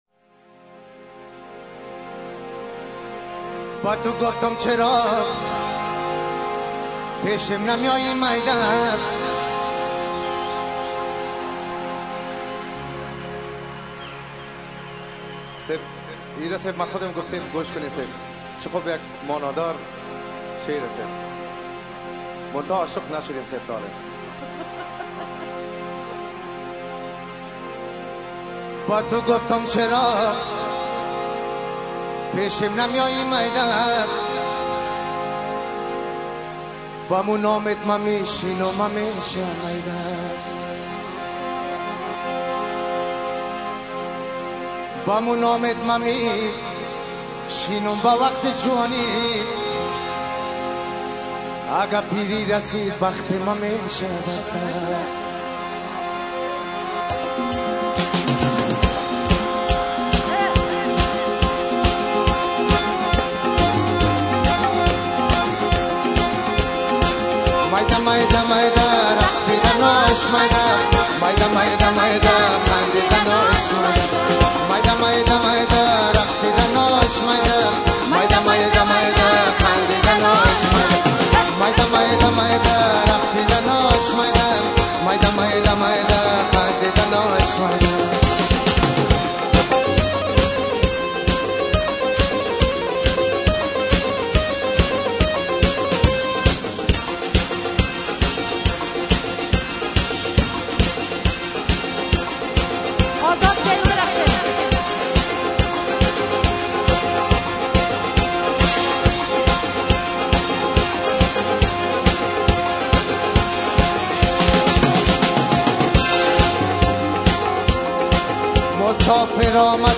Concert (germany)